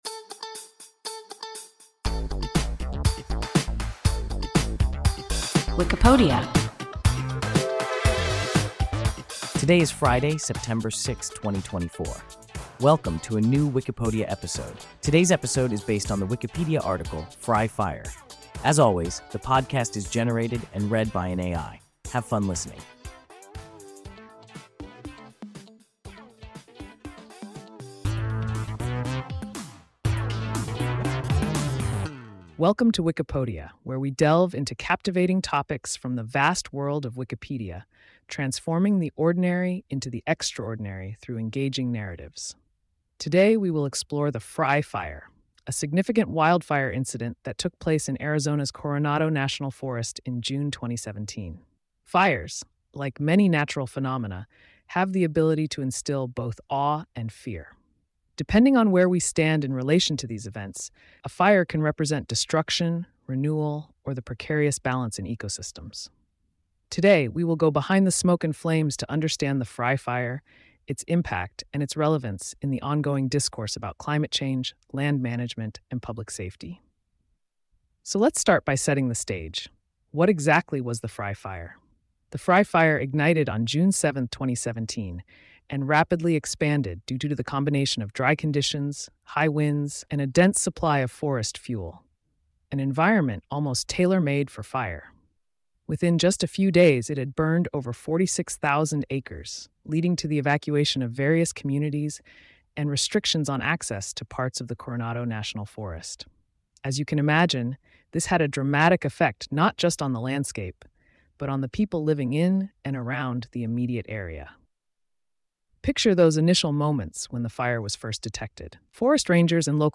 Frye Fire – WIKIPODIA – ein KI Podcast